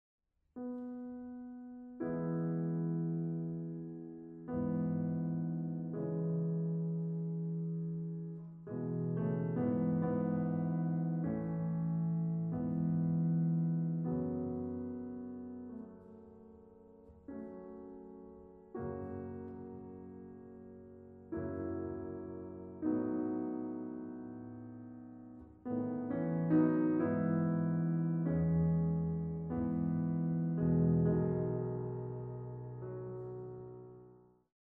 Exquisite sounds well-recorded; excellent booklet.
Piano